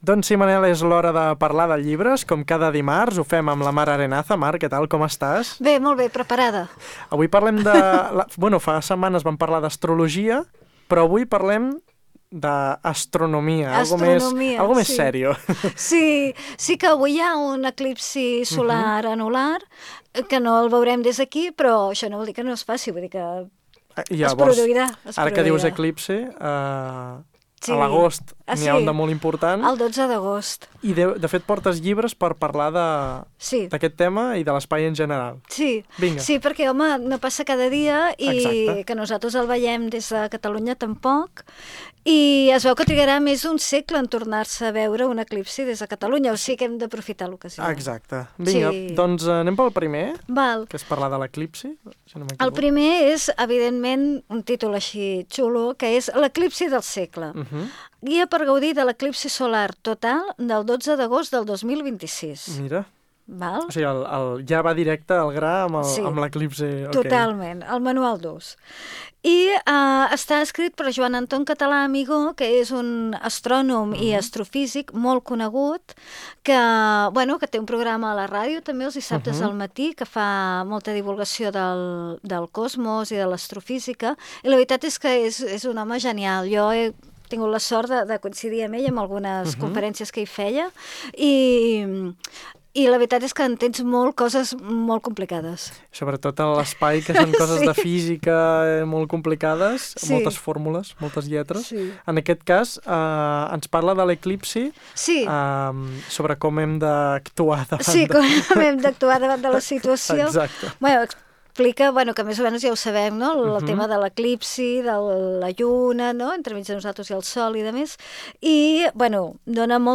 Al llarg de la conversa, parlem de com aquests llibres ens ajuden a entendre millor l’univers, els planetes, les estrelles i els grans misteris del cosmos, tot combinant divulgació científica i curiositat humana.